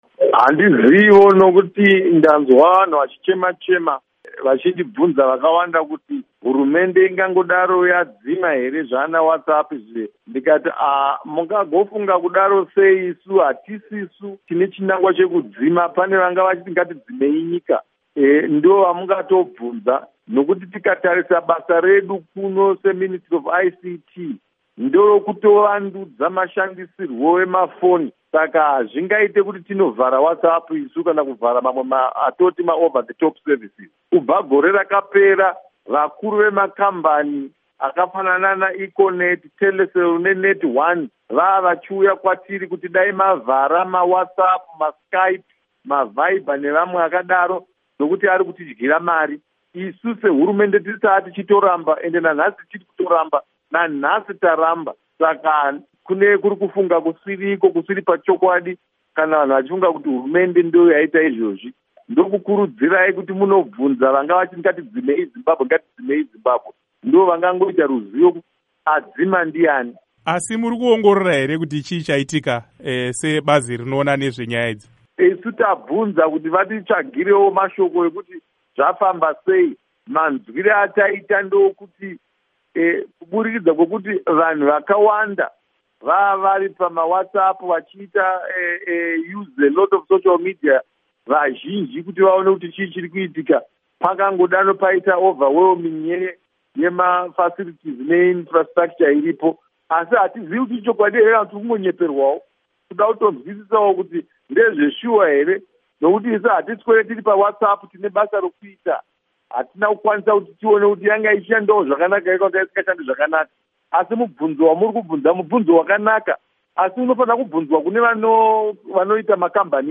Hurukuro naVaSupa Mandiwanzira